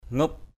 /ŋup/ (đg.) gục = baisser, incliner. ngup akaok Z~P a_k<K gục đầu = incliner la tête.